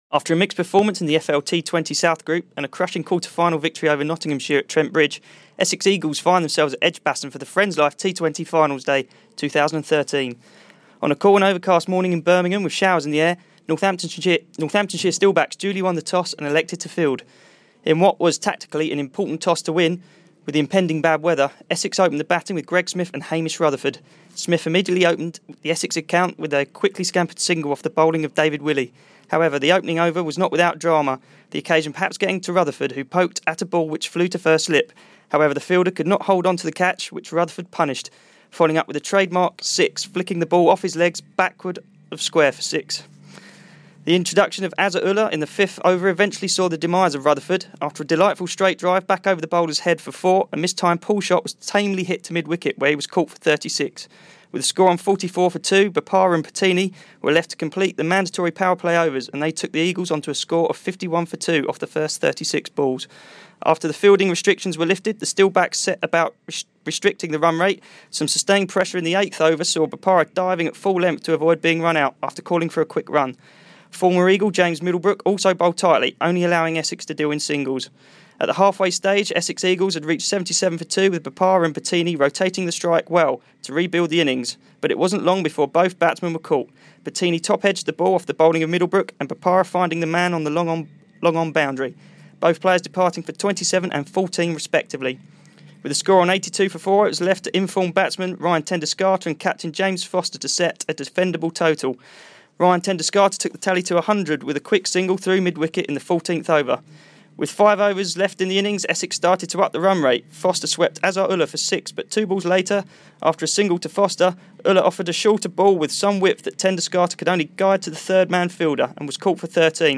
Match report at the end of the Essex Eagles innings v Northants Steelbacks at Finals Day 2013